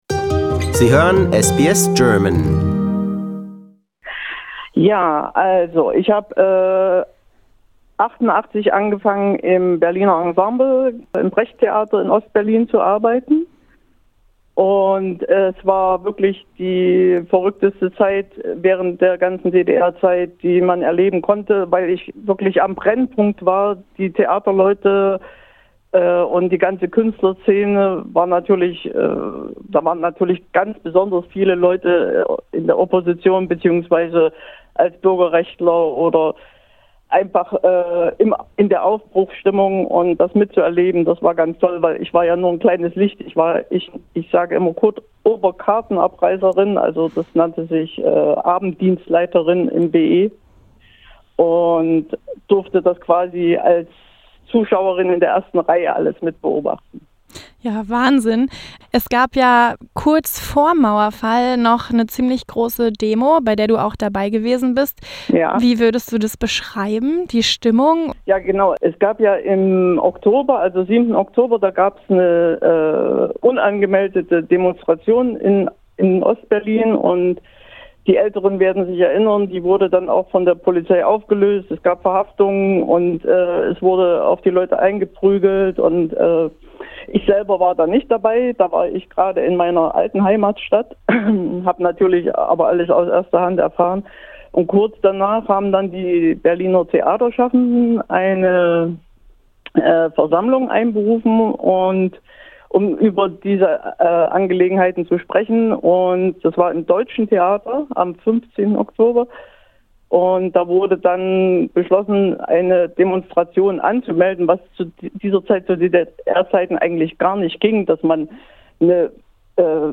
In this interview she tells us about a huge demonstration at Berlin-Alexanderplatz, five days prior, on 4 November 1989.